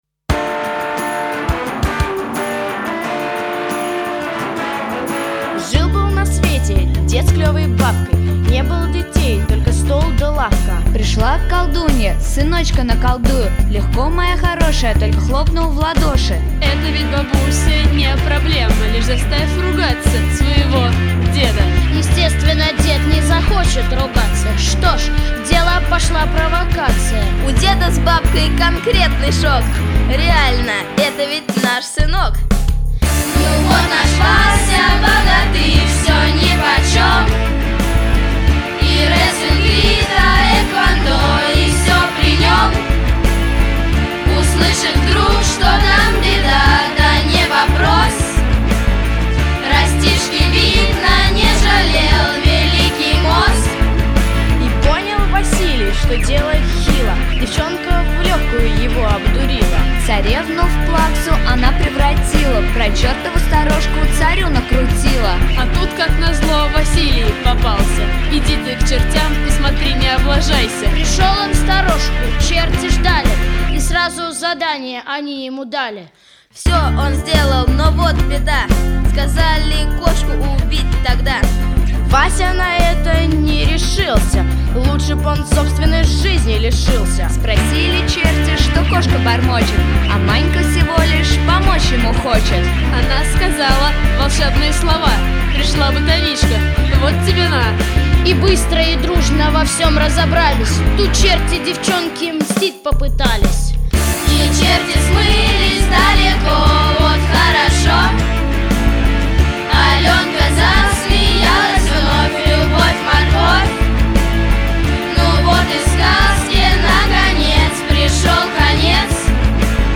Песни исполняют: Участники спектаклей
Записано в студии Easy Rider в сентябре-ноябре 2005 года